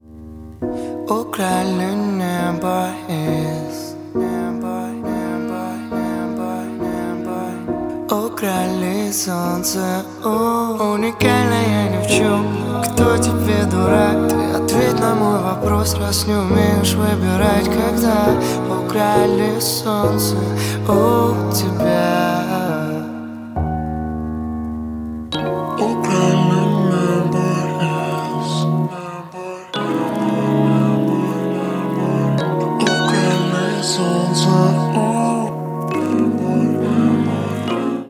• Качество: 128, Stereo
лирика
Хип-хоп
спокойные
красивая мелодия
романтичные